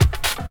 37DR.BREAK.wav